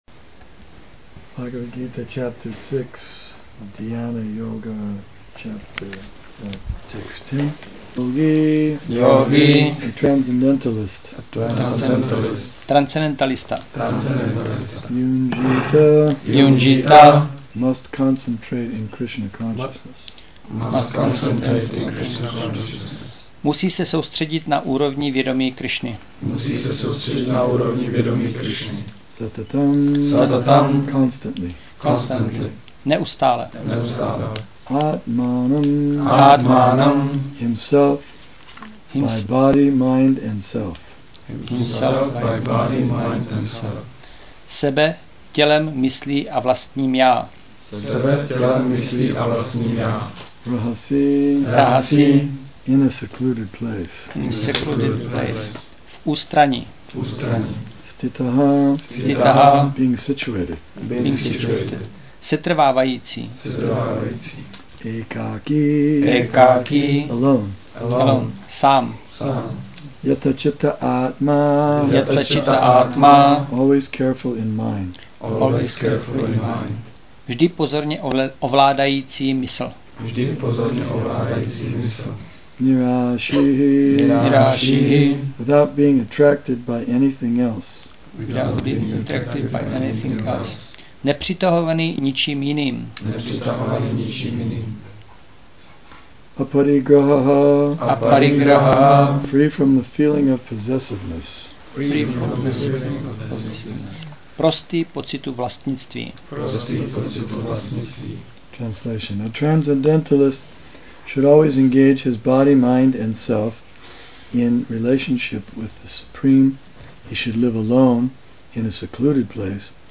Přednáška BG-6.10 – Šrí Šrí Nitái Navadvípačandra mandir